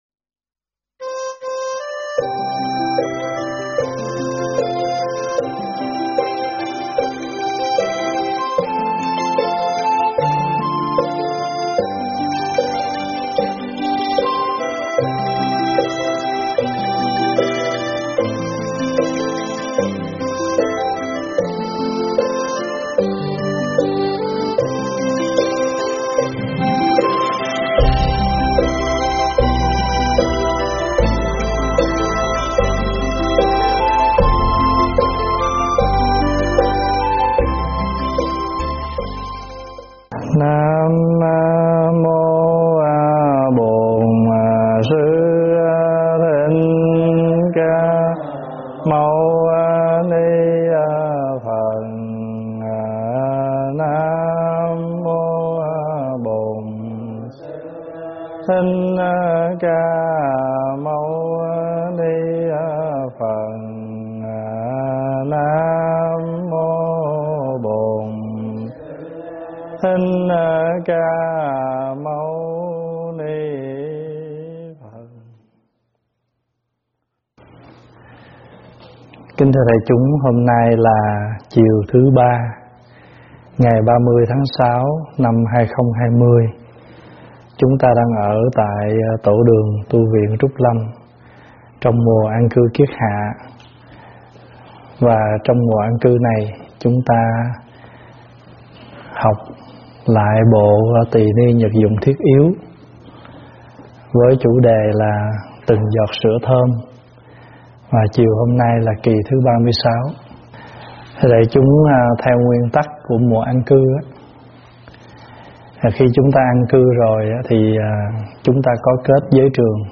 pháp thoại
giảng tại Tv Trúc Lâm